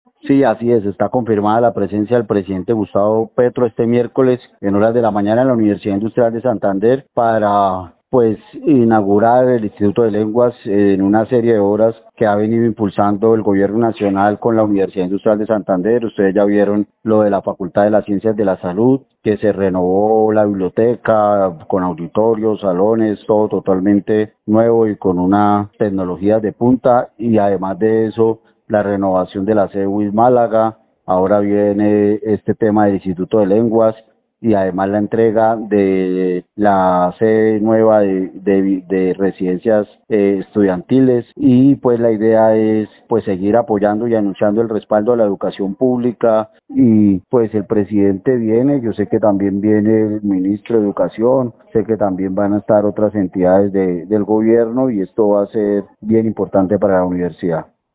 Concejal Jorge Flórez, del Pacto Histórico